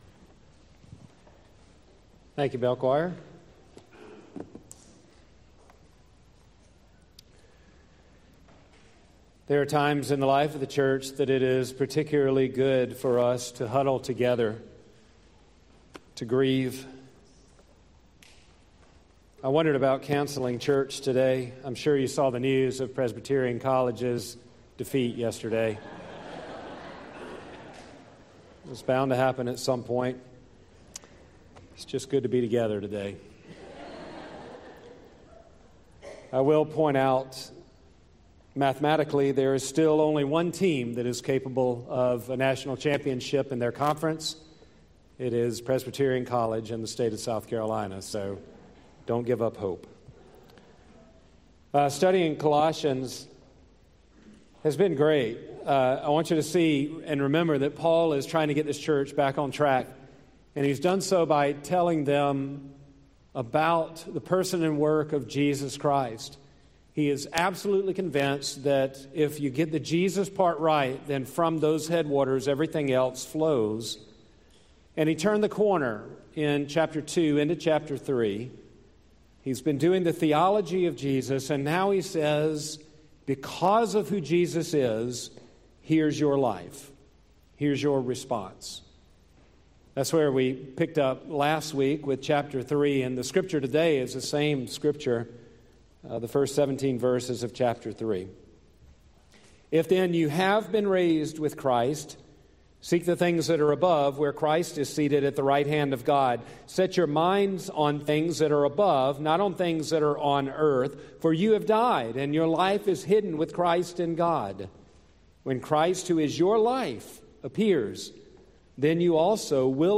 Tagged with sermon